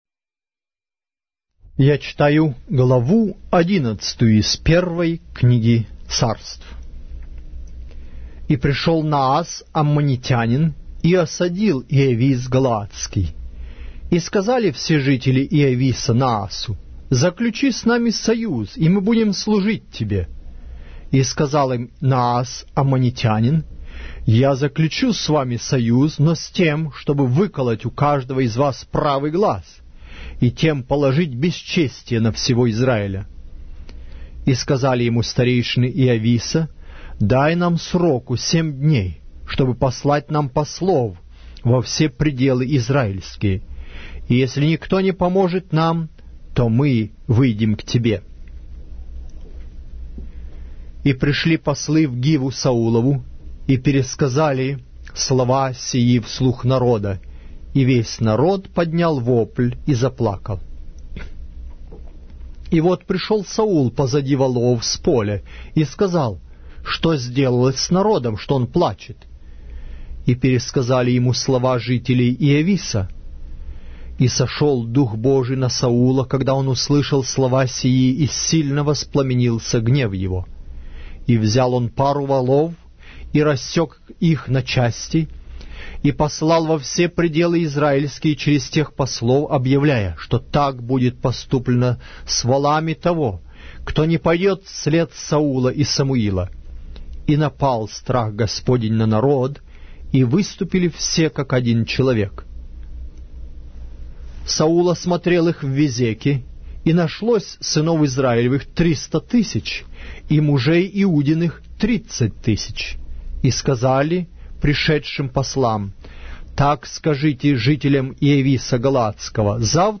Глава русской Библии с аудио повествования - 1 Samuel, chapter 11 of the Holy Bible in Russian language